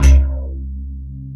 BACKBASSC2-R.wav